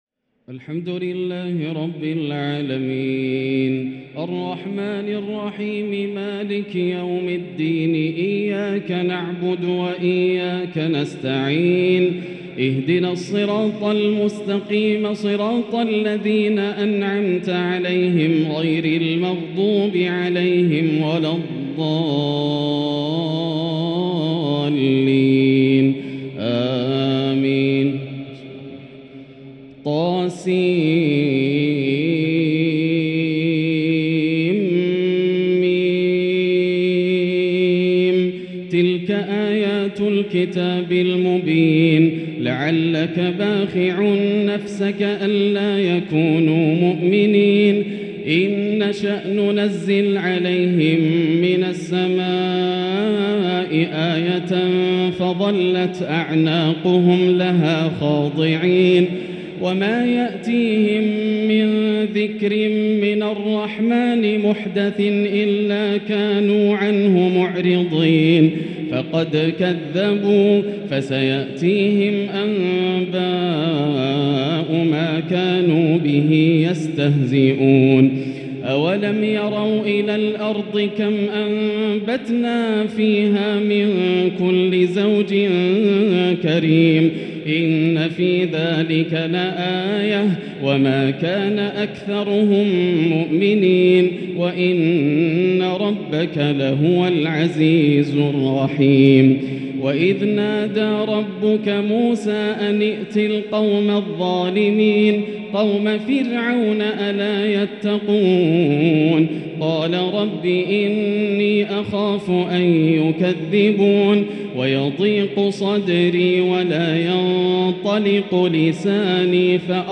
تراويح ليلة 23 رمضان 1444هـ فواتح سورة الشعراء (1-175) | Taraweeh prayer 23 St night Ramadan 1444H from surah Ash-Shuara > تراويح الحرم المكي عام 1444 🕋 > التراويح - تلاوات الحرمين